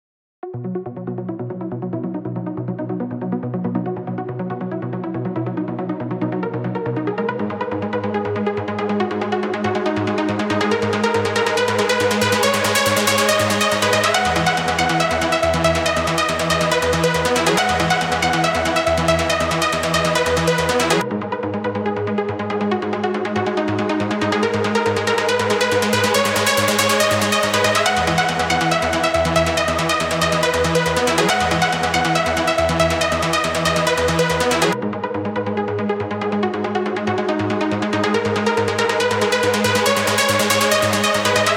Trance песочница (крутим суперпилы на всём подряд)
Вот начал прям за здравие, позитив! На 0.17 "задумчивость". На 0.24 поворот в "грусть и печаль".